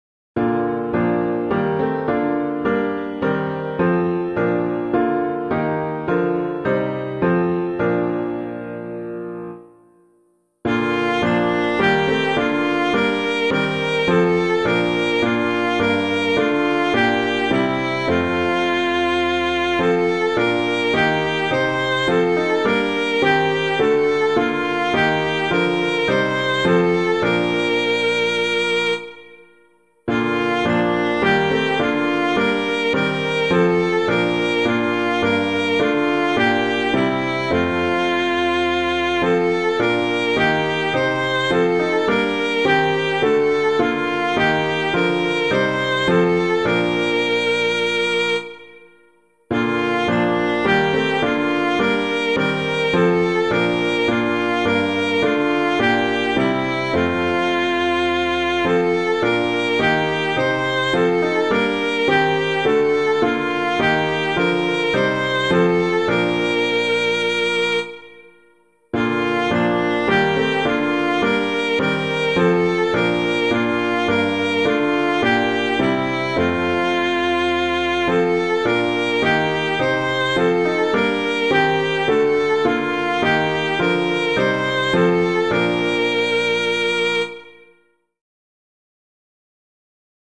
piano
Be Still My Soul before the Lord [Stuempfle Jr. - SAINT ANNE] - piano.mp3